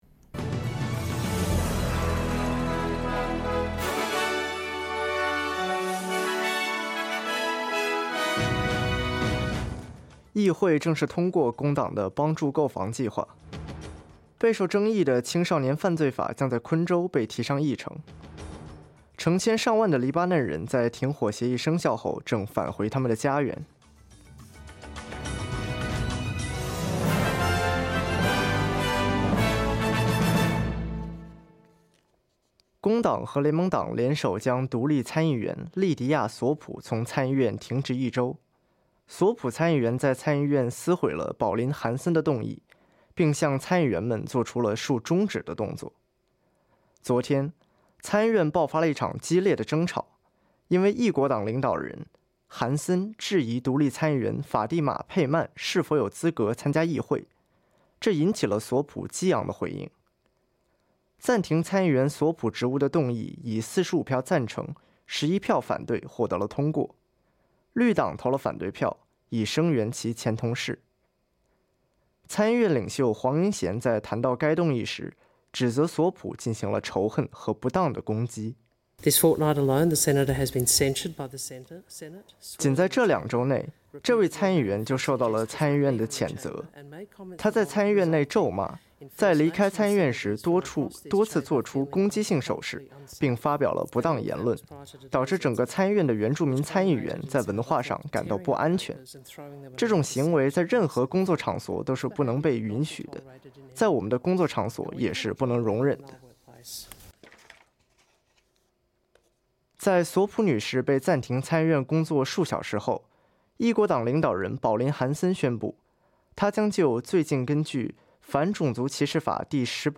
SBS 早新闻（2024年11月28日）